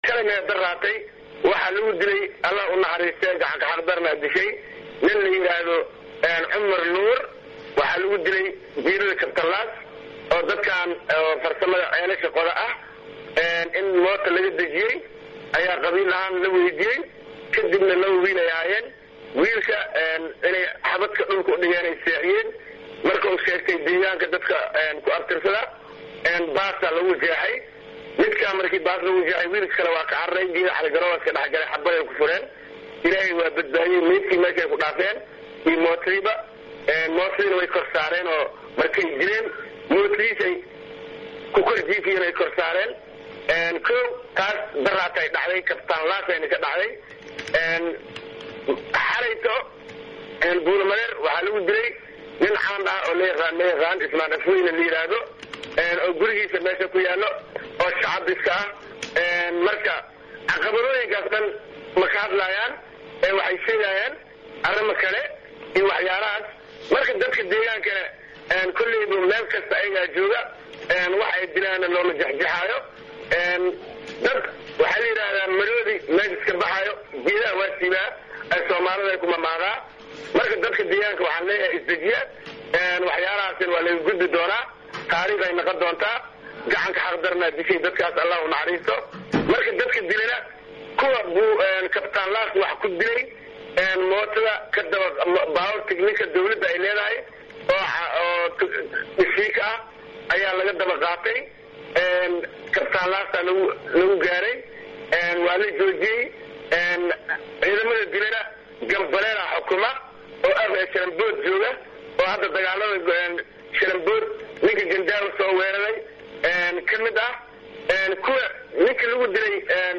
Gudoomiyihii hore ee degmada Marka Maxamed Cismaan Cali Yariisow oo la hadley warbaahinta ayaa sheegay in maleeshiyaad ku hubeysan tiknikooyinka dowladda Federalka ay 48-dii saac ee la soo dhaafay dilal iyo boob ka geysteen deegaano hoos yimaado degmada Marka ee xarunta gobolka Sh.hoose.